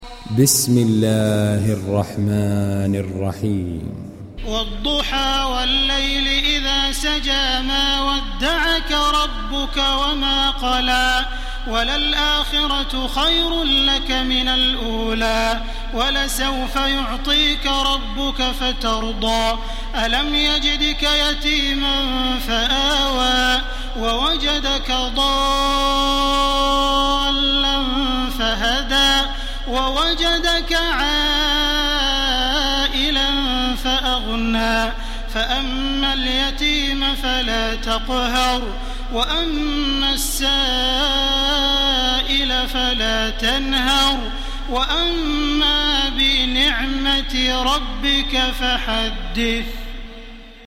Sourate Ad Dhuha du cheikh tarawih makkah 1430 en mp3, lire et telecharger sourate Ad Dhuha